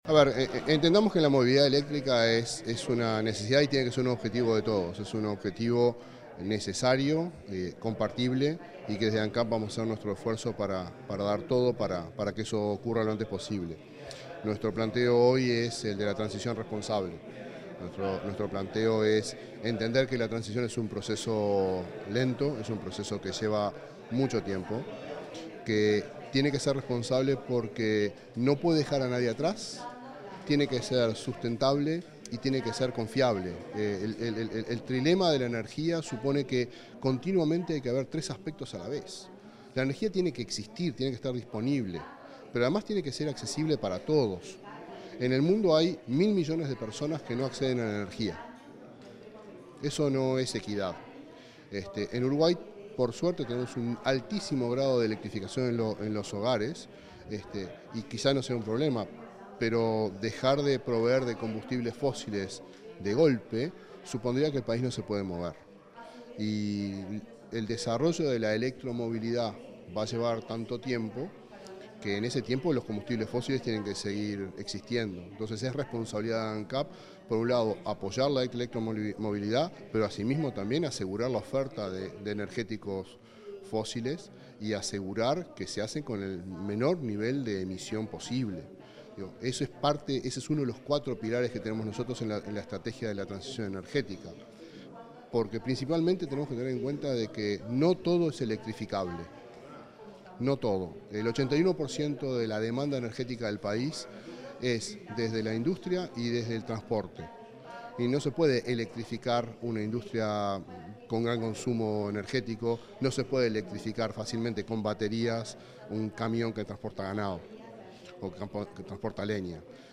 Entrevista al presidente de Ancap, Alejandro Stipanicic
El presidente de Ancap, Alejandro Stipanicic, dialogó con Comunicación Presidencial luego de participar en el Segundo Foro Internacional de Movilidad